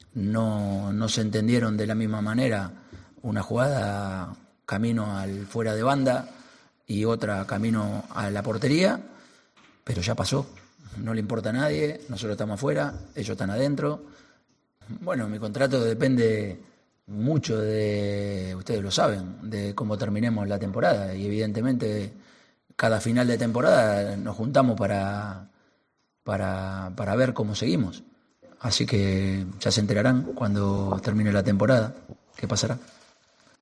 "Ante todo, tenemos que empezar por una situación; desde que estoy, nunca he leído unas declaraciones como las que ha hecho Miguel Ángel. Desde que estoy, nunca hemos buscado excusas, siempre hemos buscado ayudar, y más con el crecimiento del VAR, que hacen que el juego sea mucho más justo. Evidentemente, ante una situación extremadamente clara, expresó lo que muchos se callan; él lo dijo con total naturalidad, que es lo más sano", declaró en rueda de prensa.